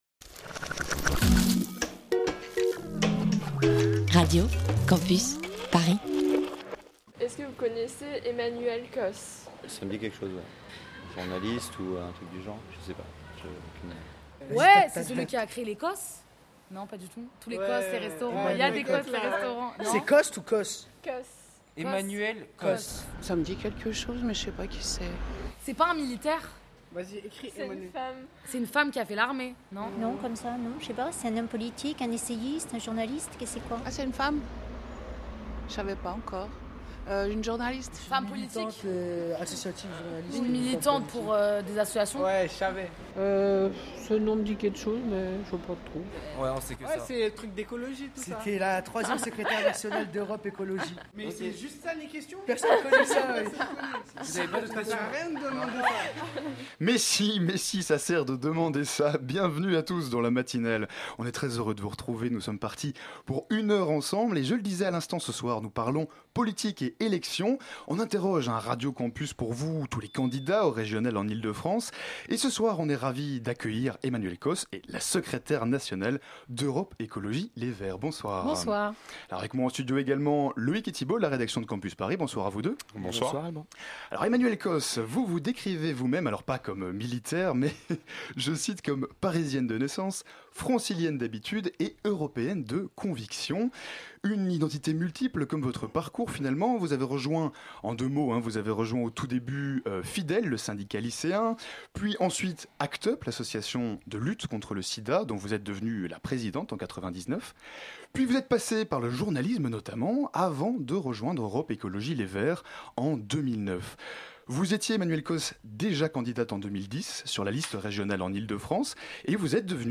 Entretien avec Emmanuelle Cosse, secrétaire nationale d'Europe Ecologie/Les Verts.